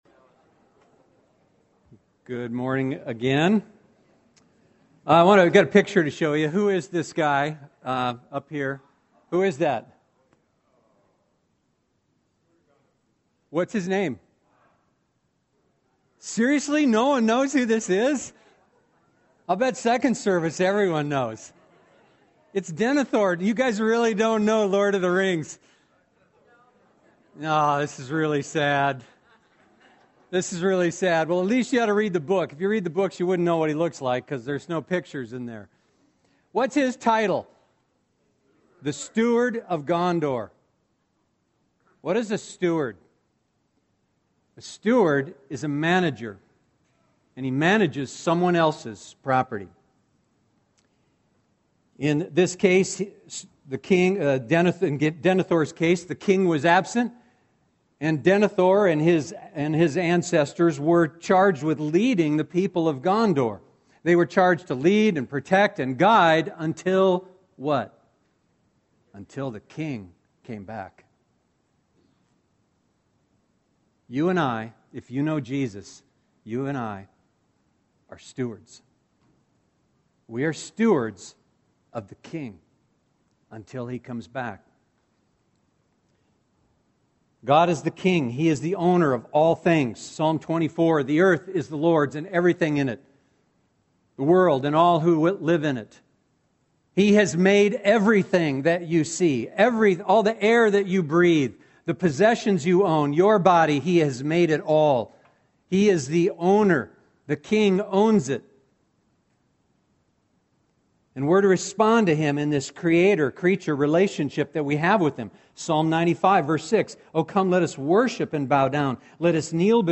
Let’s pray together.